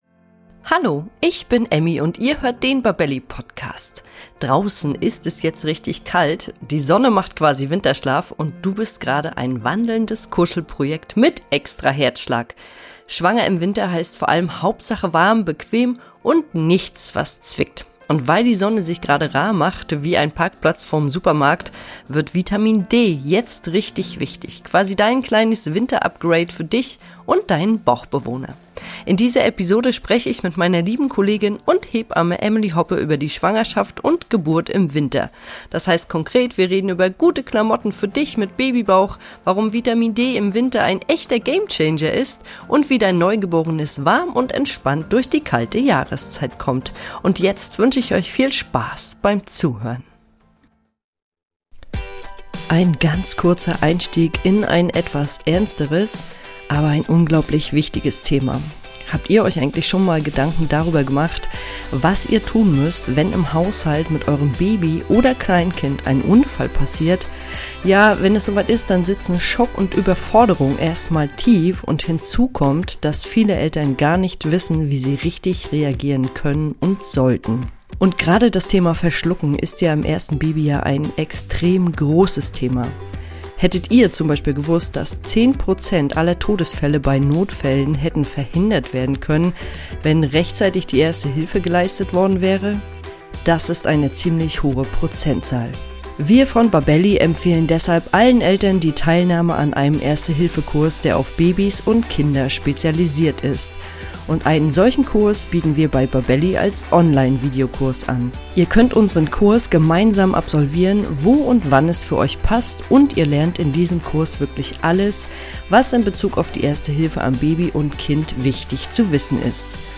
Warm, ruhig und Schritt für Schritt durch den Winter – das ist der Vibe.